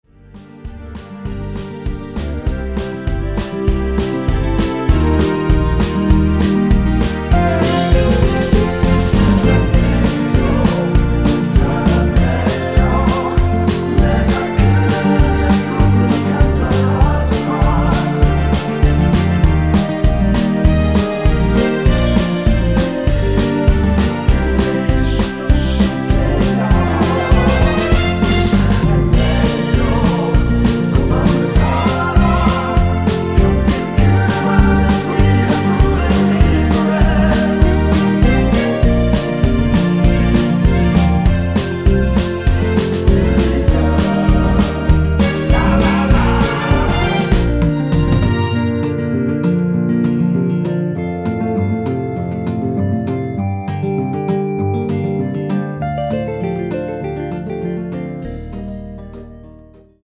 전주가 길어서 8마디로 편곡 하였으며
원키에서(-2)내린 (1절+후렴)으로 진행되는 멜로디와 코러스 포함된 MR입니다.
Bb
앞부분30초, 뒷부분30초씩 편집해서 올려 드리고 있습니다.
중간에 음이 끈어지고 다시 나오는 이유는